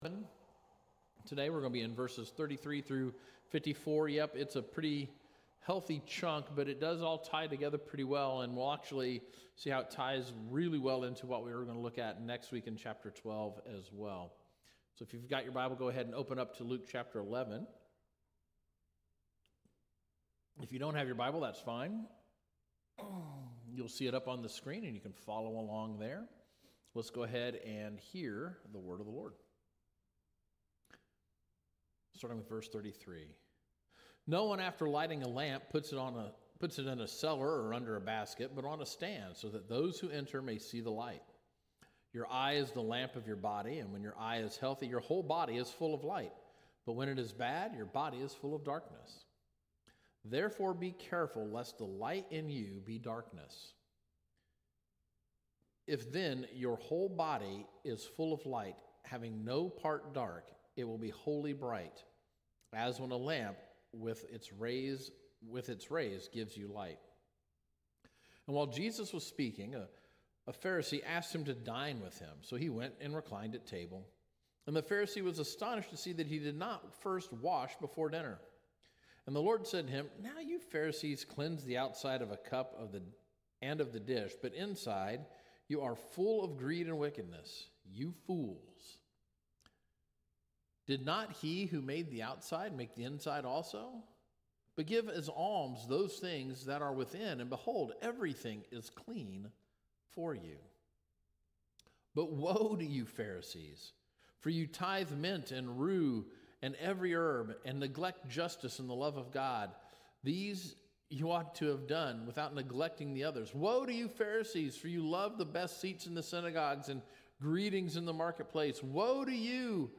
Sermons by Calvary Heights Baptist Church